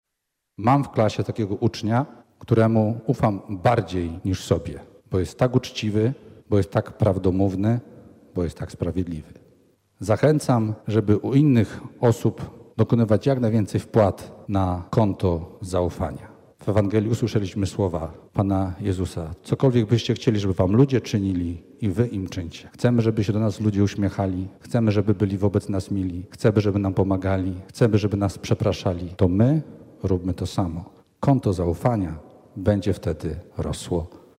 Mszą Św. w Katedrze Wrocławskiej rozpoczęła się inauguracja roku szkolnego 2025/2026 dla placówek katolickich Archidiecezji Wrocławskiej.